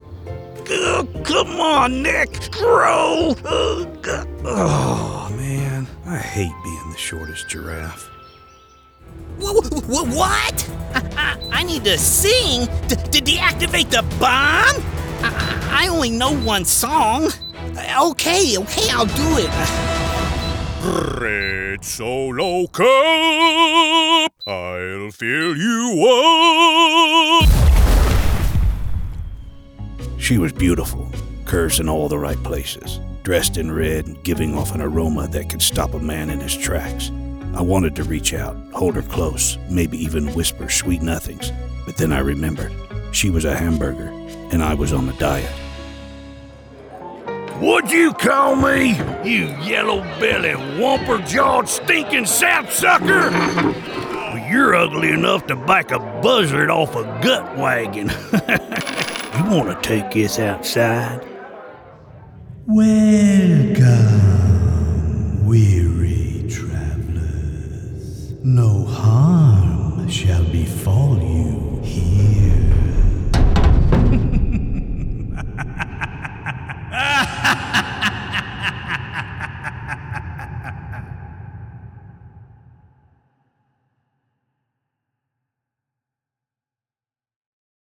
Male
Southern, Anthem, storyteller, conversational, non announcer, movie trailer, Simple, clear, direct, honest, trustworthy, confident, enthusiastic, conversational, Amiable, Announcer, Approachable, Athletic, Attitude, Attractive, Audiobook, Authoritative, Bank, Baritone, Believable, Bright, Broadcast, Business, Call to Action, Calming, Caring, cool
Character / Cartoon
Animation Demo Reel